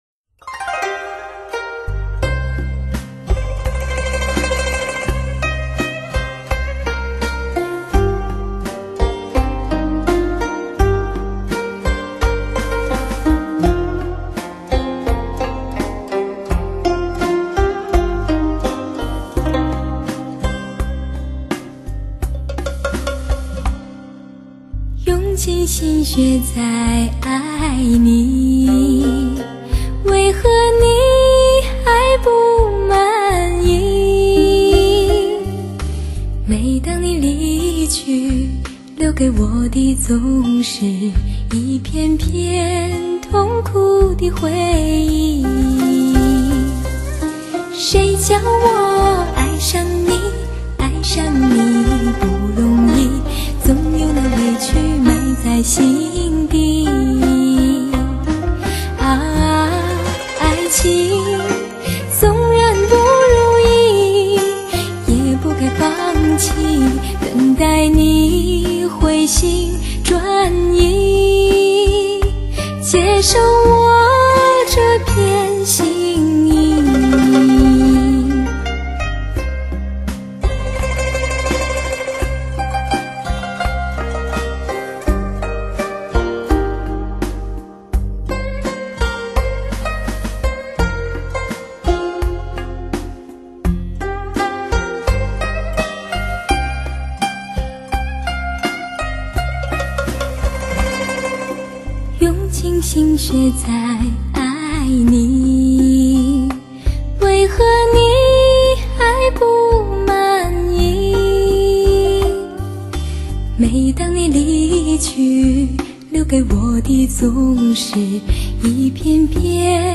所属分类：音乐:发烧/试音:流行
让优美的歌声伴随你，专为汽车音响量身定做的HI-FI唱片，令你在飞驰中享受近乎无暇的美妙音乐。